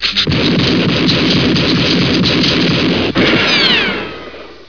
riflshts.wav